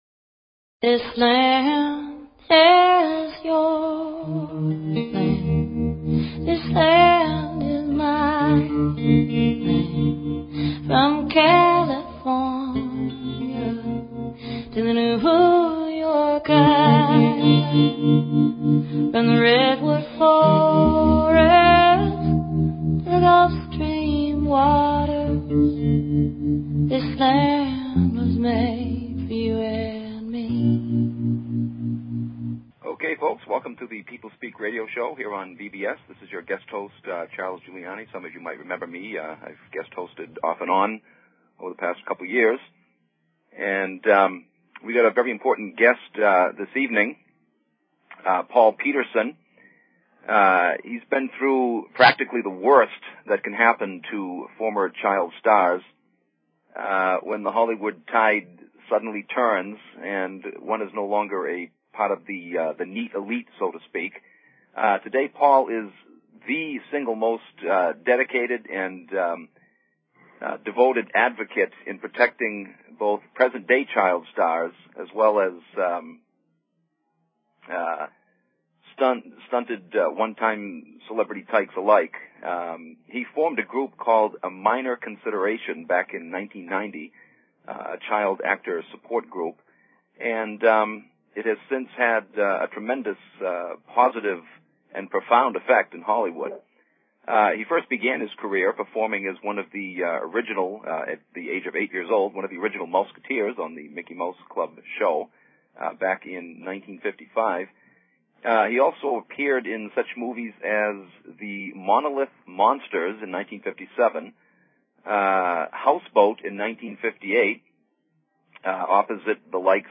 Talk Show Episode, Audio Podcast, The_People_Speak and Courtesy of BBS Radio on , show guests , about , categorized as
The show features a guest interview from any number of realms of interest (entertainment, science, philosophy, healing, spirituality, activism, politics, literature, etc.).
The radio show name, The People Speak, is based on the idea of allowing our audience - the People - a chance to interact with the guests during the hour, and we take phone or text questions from them during the interview.